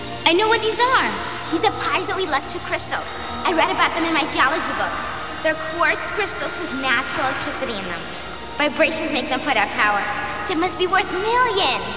one of the nerdy kids tells them she knows exactly what they are.